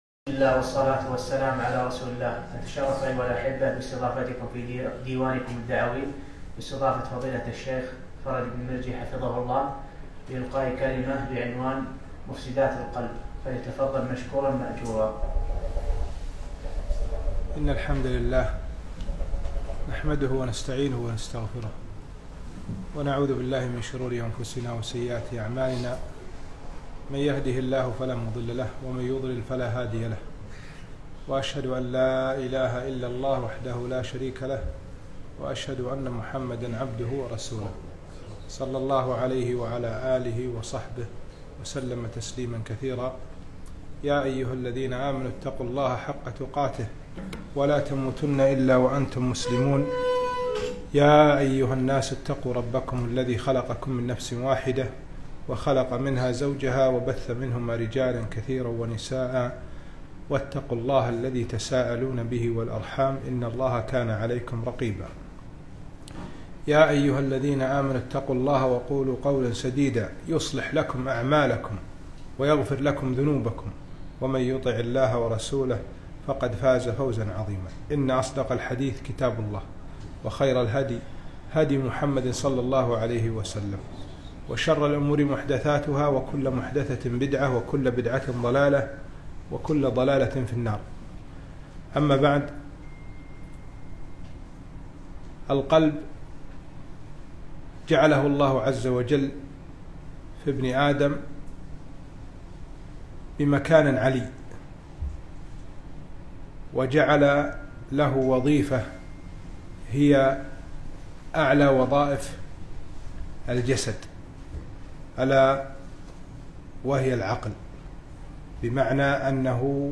محاضرة - مفسدات القلب 2-4-1443